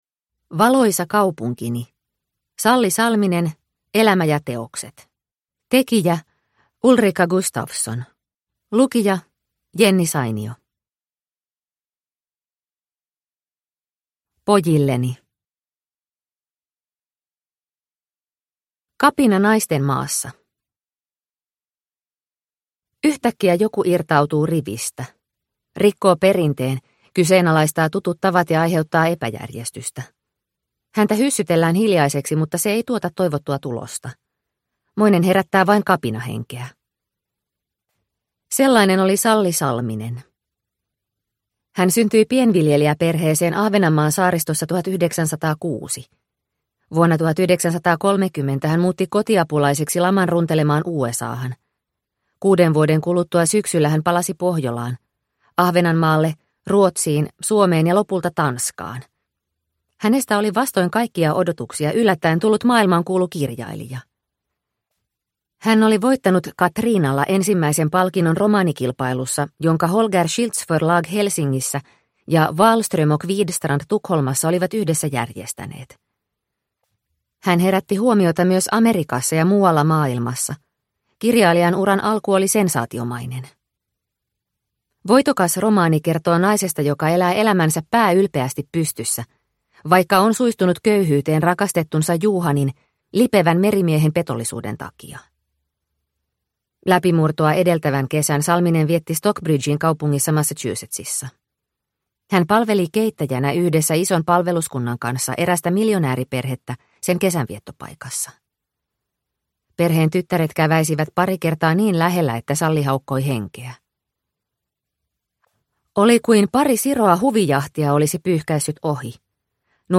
Valoisa kaupunkini – Ljudbok – Laddas ner